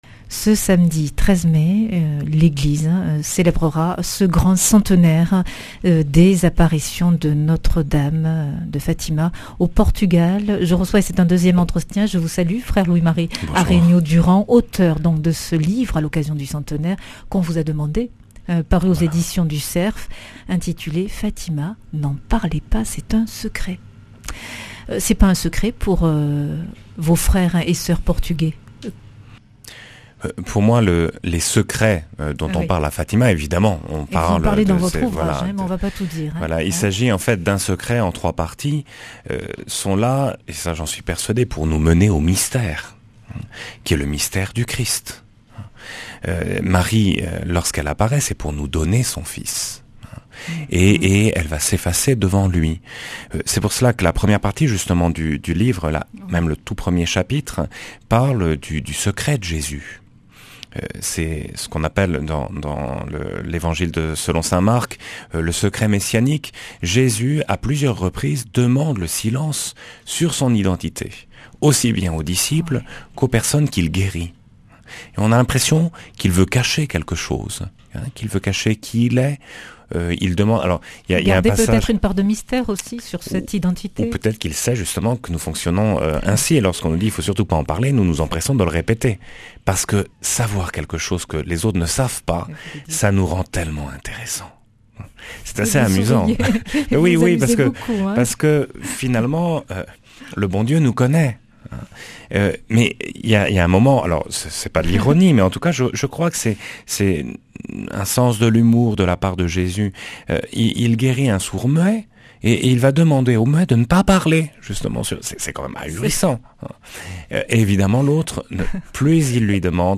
Speech
Une émission présentée par